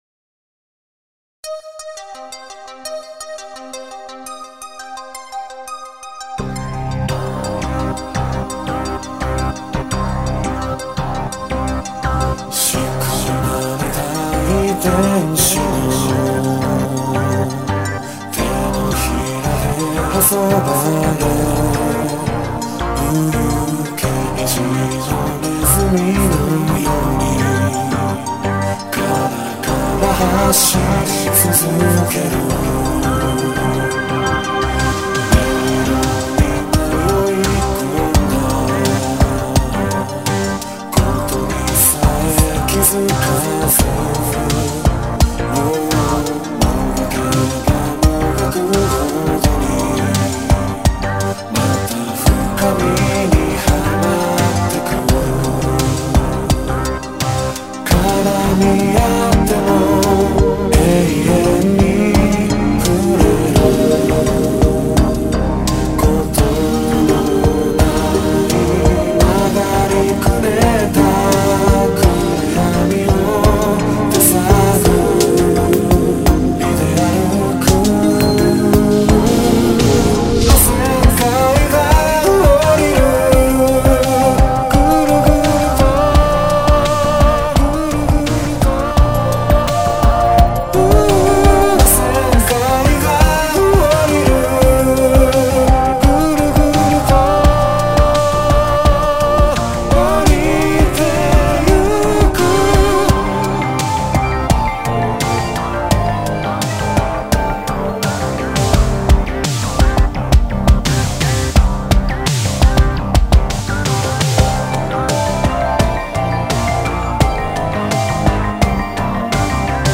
「歌モノ部門」最優秀作品です。
・Synthesizer V Ryo